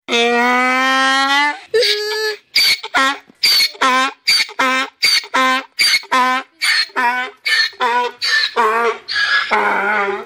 Звук осел орет - Donkey
778_donkey.mp3